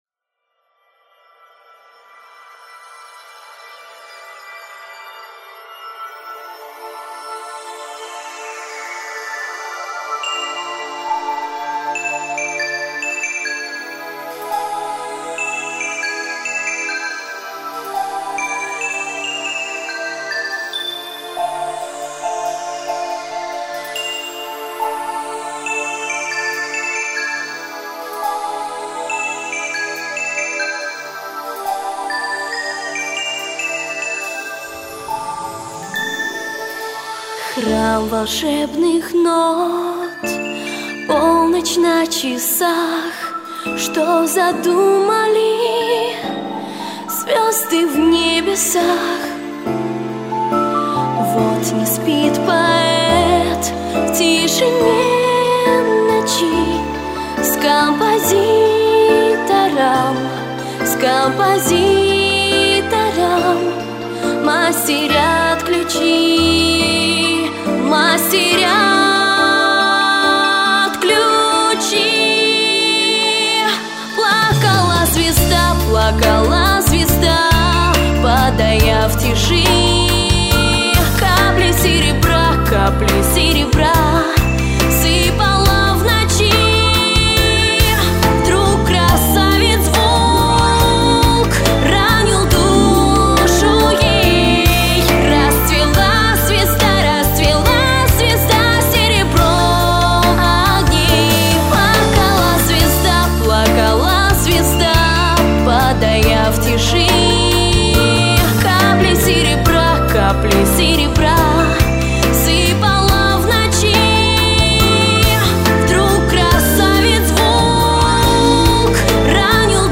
Всі мінусовки жанру Ballad
Плюсовий запис